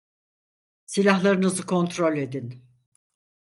Phát âm là (IPA) /kon.tɾol/